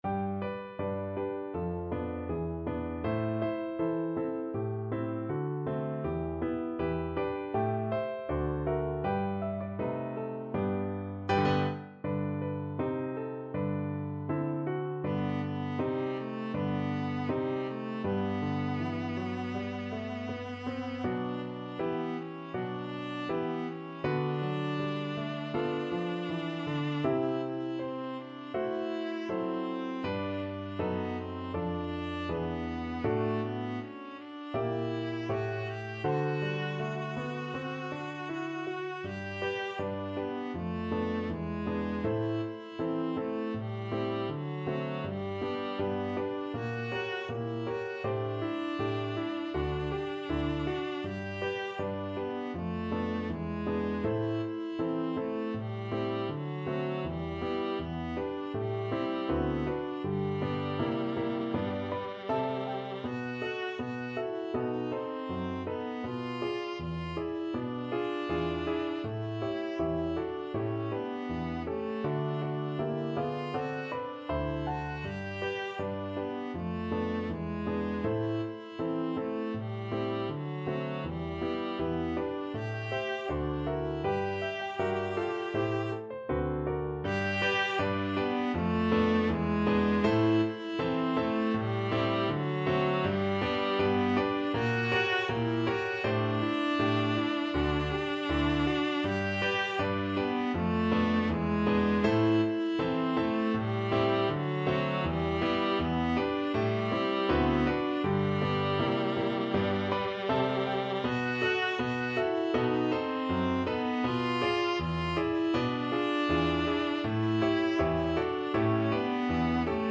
Viola version
2/2 (View more 2/2 Music)
~ = 100 Moderato =80
Viola  (View more Easy Viola Music)
Pop (View more Pop Viola Music)